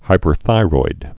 (hīpər-thīroid)